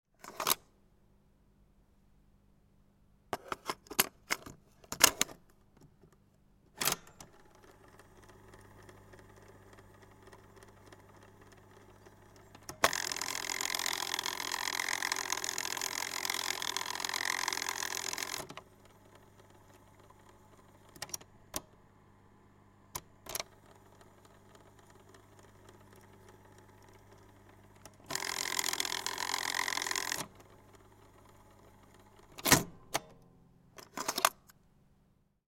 Weltron Mod. 2004 radio cassette recorder